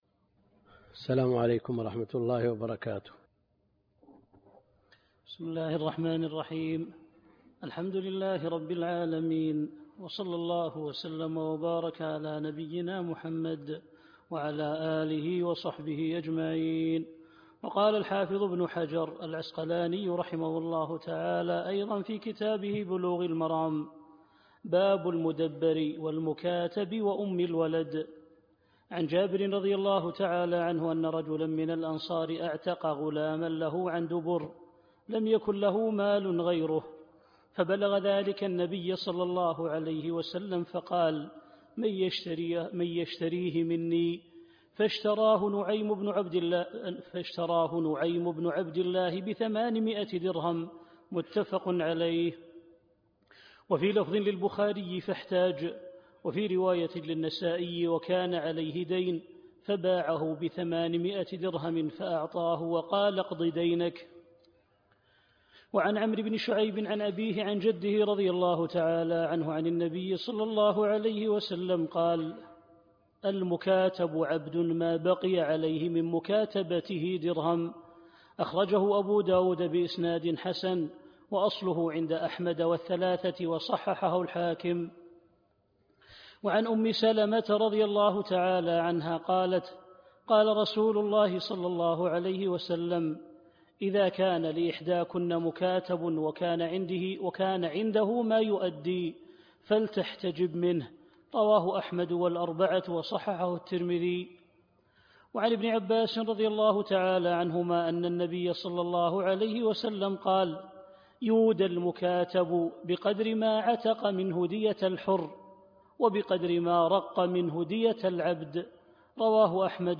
الدرس (3) كتاب العتق من بلوغ المرام - الدكتور عبد الكريم الخضير